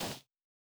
Bare Step Snow Hard B.wav